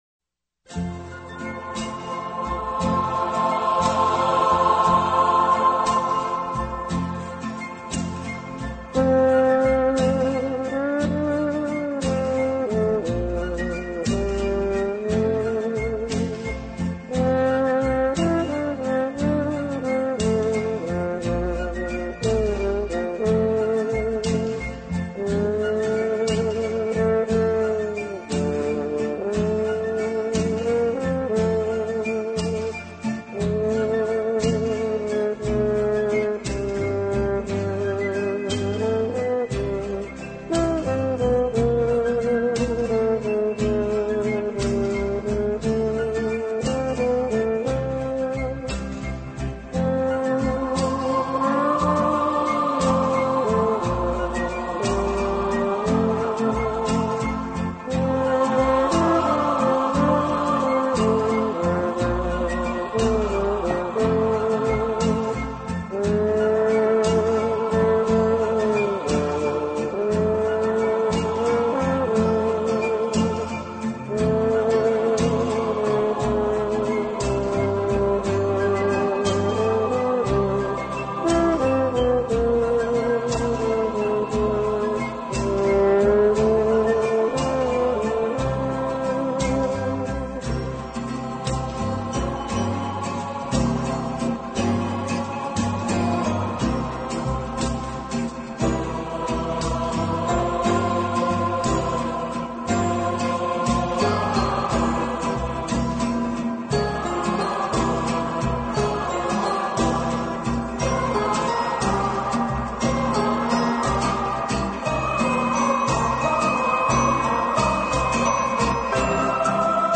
【顶级轻音乐】